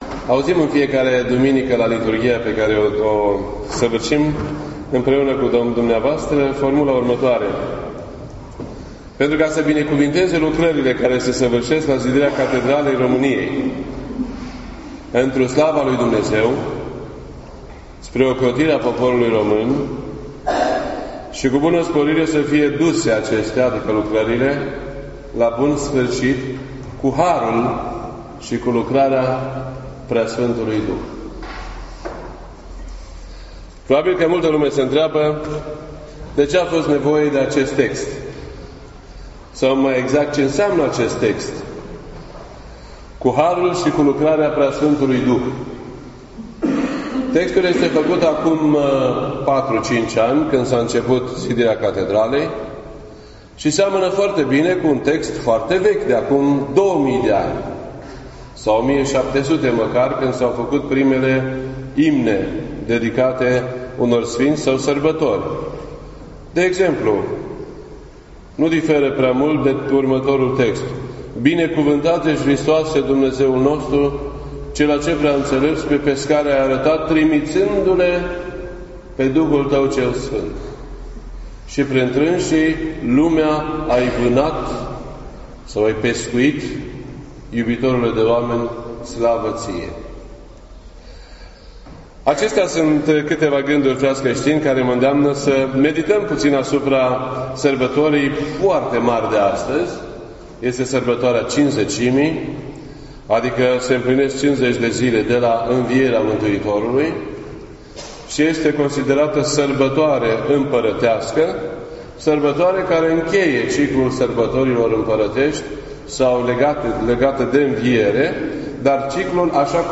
This entry was posted on Sunday, June 4th, 2017 at 6:51 PM and is filed under Predici ortodoxe in format audio.